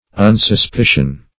Search Result for " unsuspicion" : The Collaborative International Dictionary of English v.0.48: Unsuspicion \Un`sus*pi"cion\, n. The quality or state of being unsuspecting.